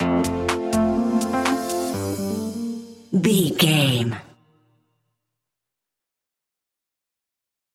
Mellow Funk Electro Stinger.
Aeolian/Minor
groovy
synthesiser
drum machine
electric piano
funky house
upbeat
funky guitar
synth bass